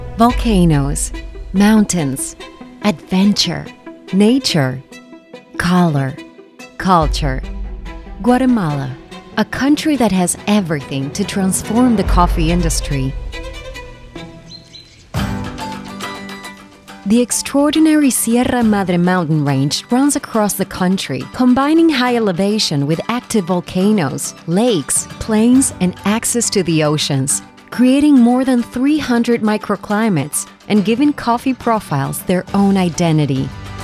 Bilingual corporate explainer for Guatemalan Coffees (ENG / SPA)